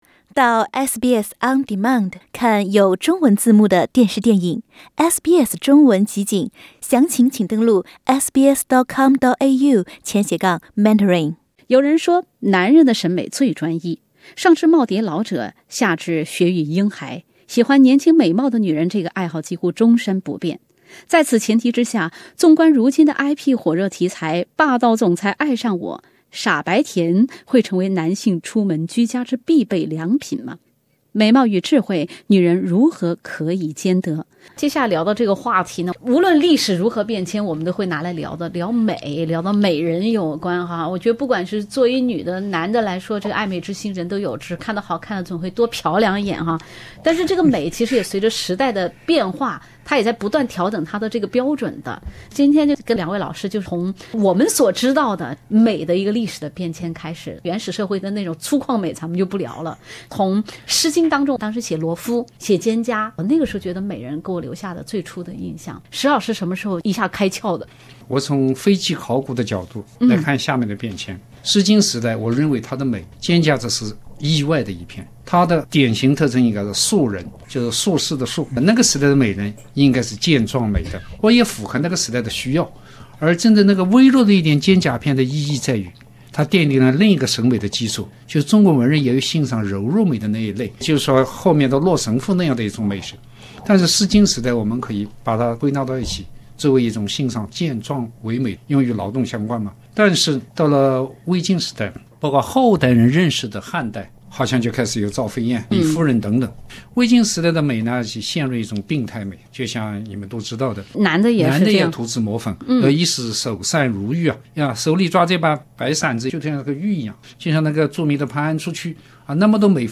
什么样的女人能被称为女神，哪些地方修炼到家才能被称为女神呢？（点击封面图片，收听完整对话）